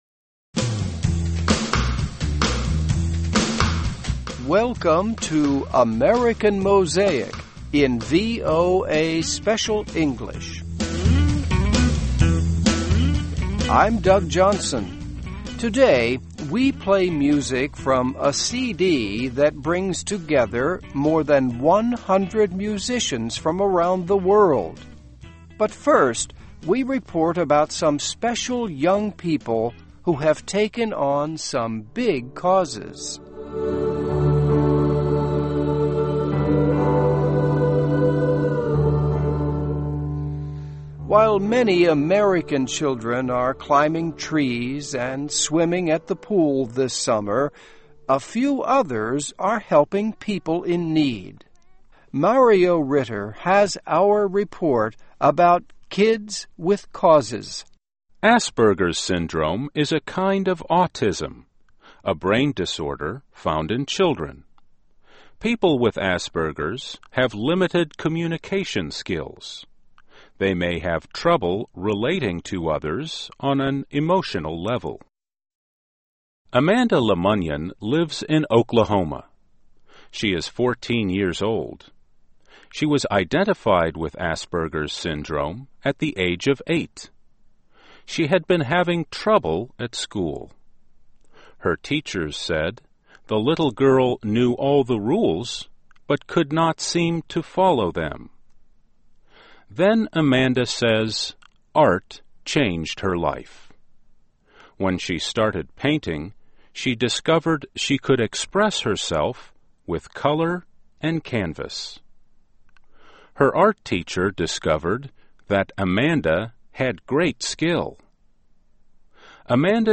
Listen and Read Along - Text with Audio - For ESL Students - For Learning English
Today, we play music from a CD that brings together more than one hundred musicians from around the world.